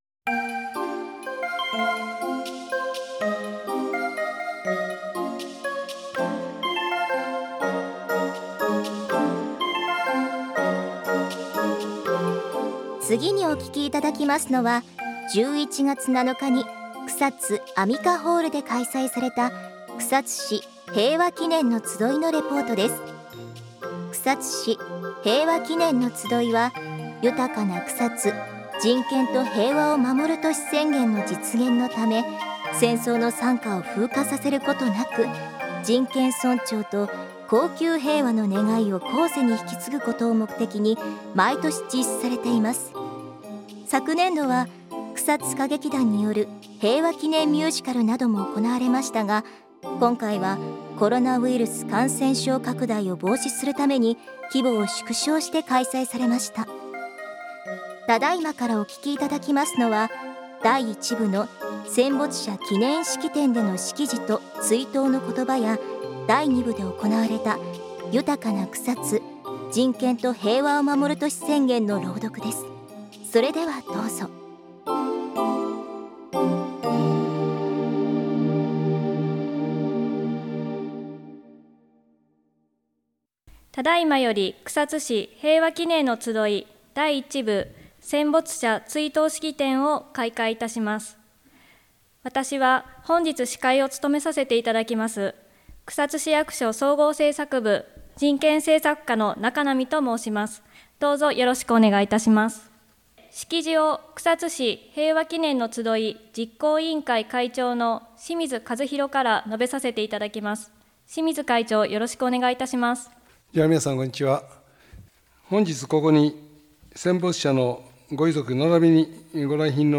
この番組は、草津市内で行われる行事や講座などを現地レポートして地域の皆様にお届けしています。